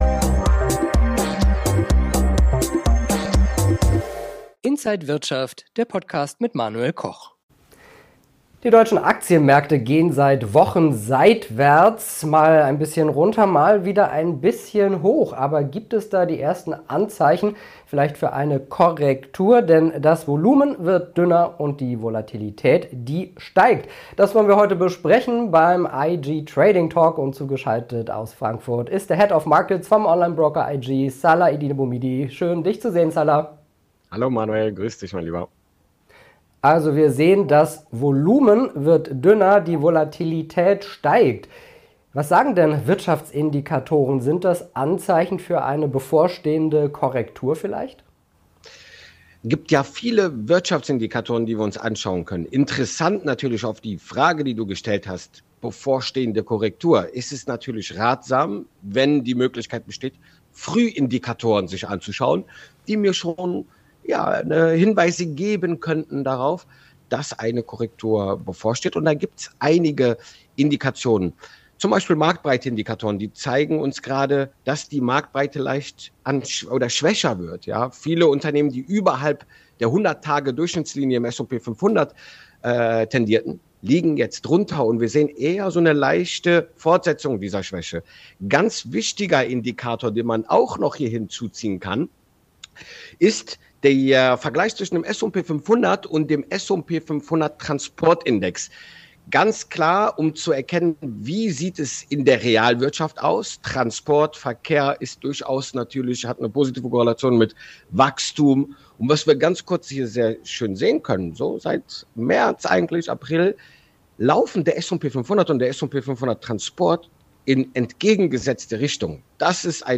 Trading Talk.